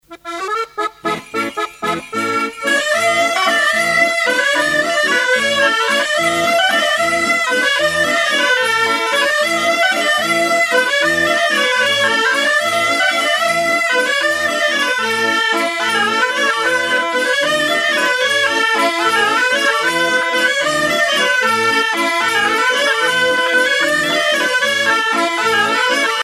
Fonction d'après l'analyste danse : bourree
Pièce musicale éditée